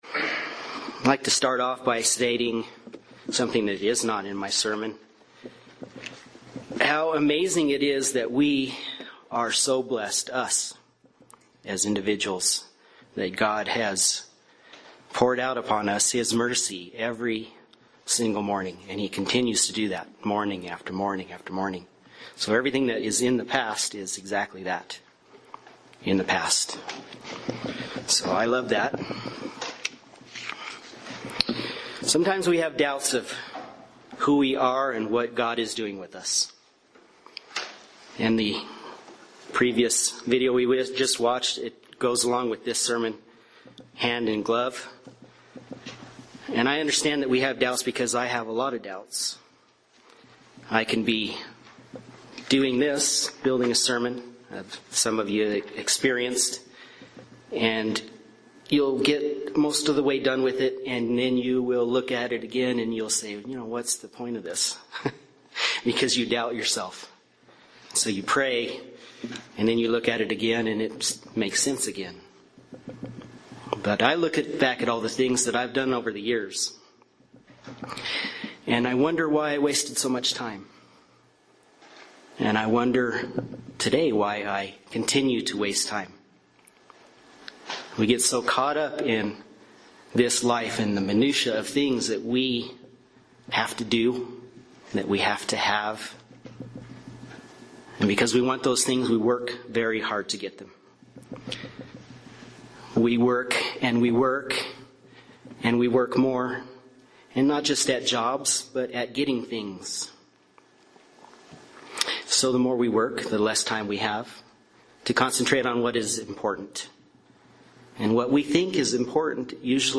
UCG Sermon Studying the bible?
Given in Albuquerque, NM